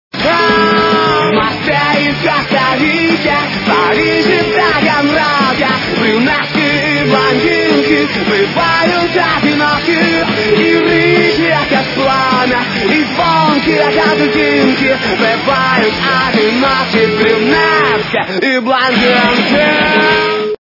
украинская эстрада